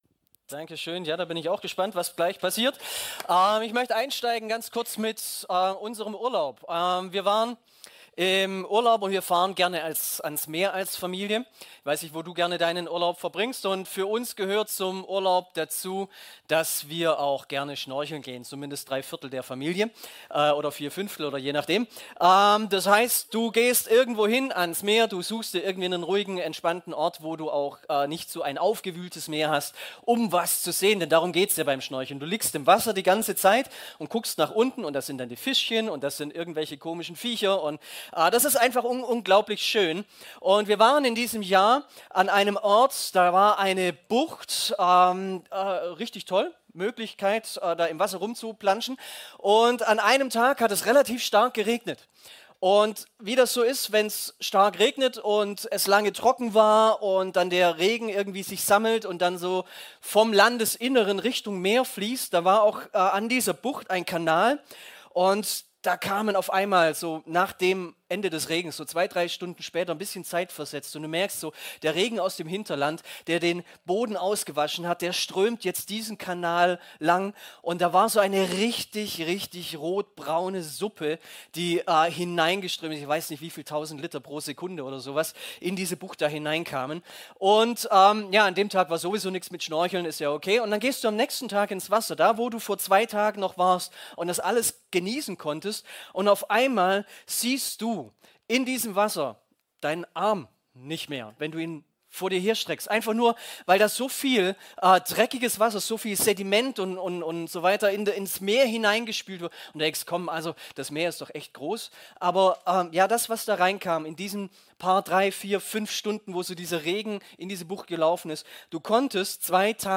Predigten | Panorama Kirche Göppingen :: verändert | gemeinsam | für Andere